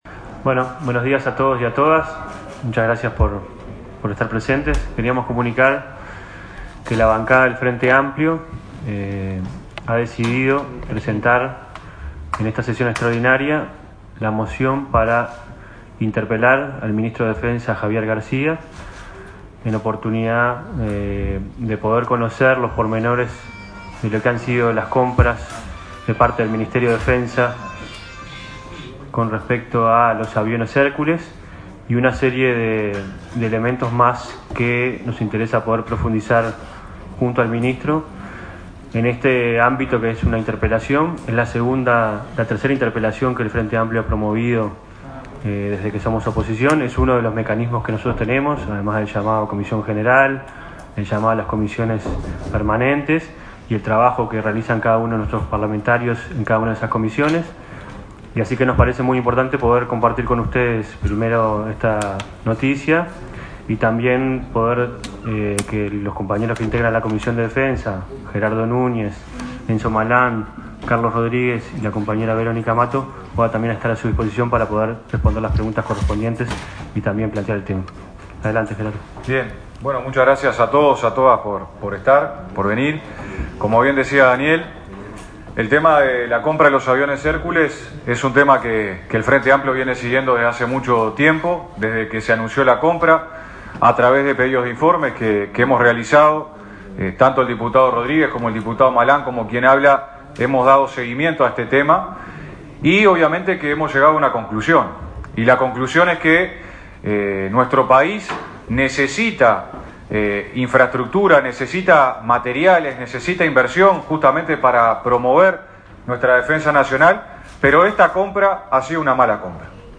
conferencia_interpelacion_Garcia.mp3